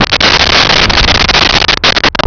Sfx Whoosh 4801
sfx_whoosh_4801.wav